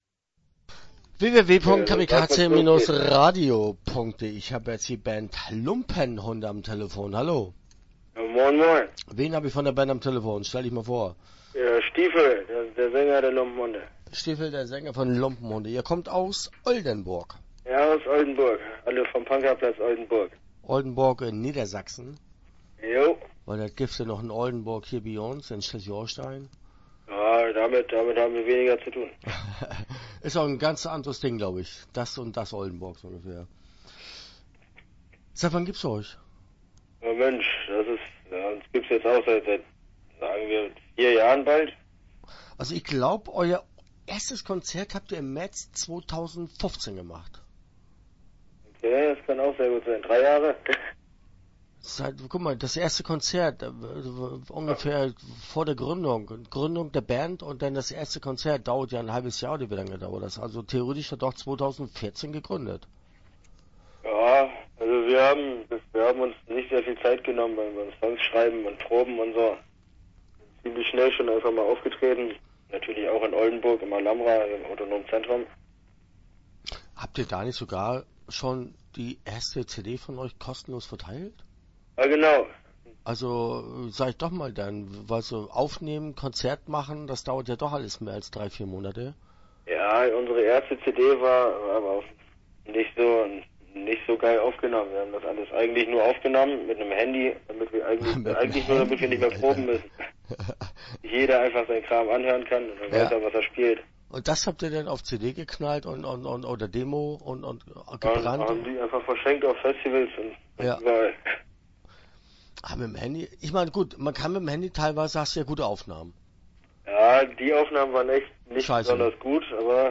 Start » Interviews » Lumpenhunde